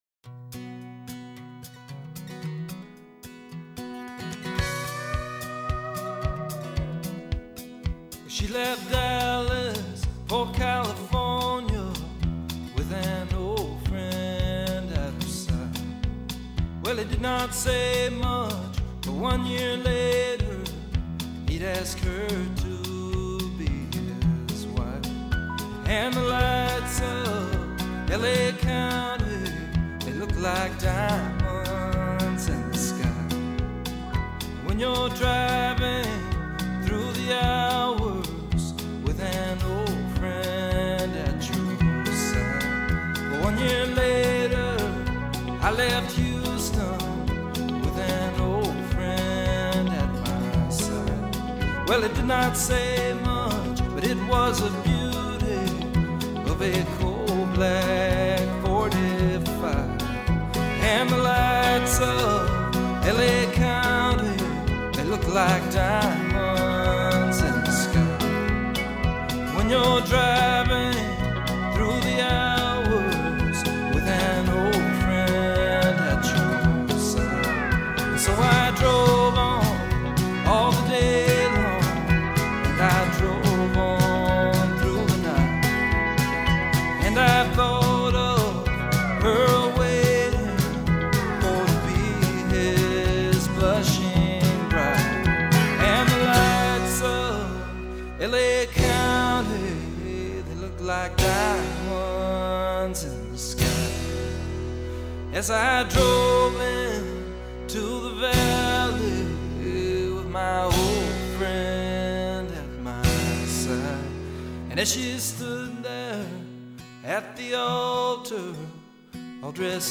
’ anchors the album’s country side.